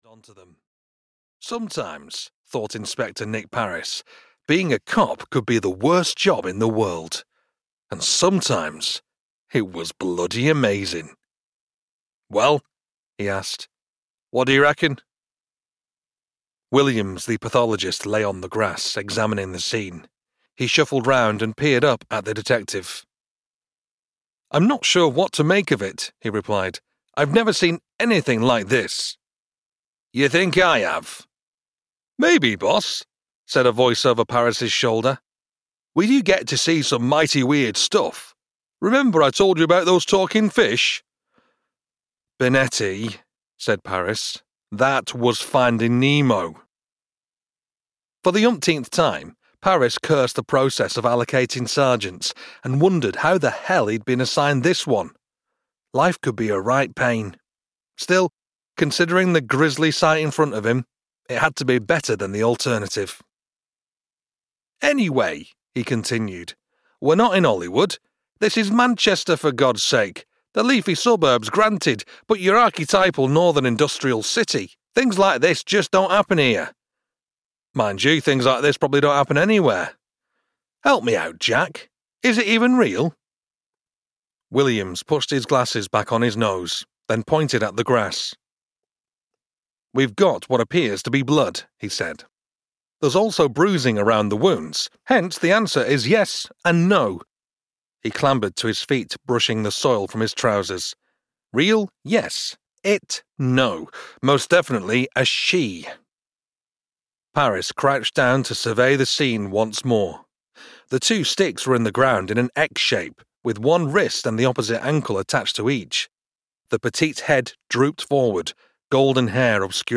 Breaking the Lore (EN) audiokniha
Ukázka z knihy